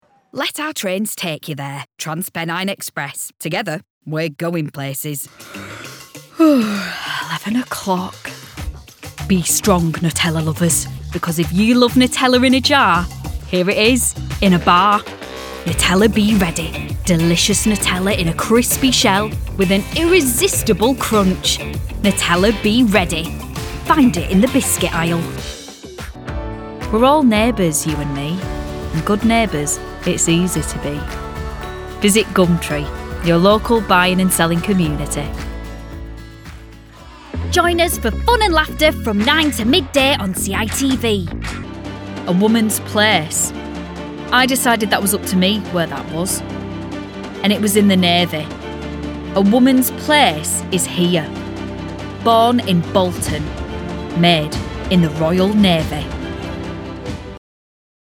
30s-40s. Female. Lancashire. Studio
Commercials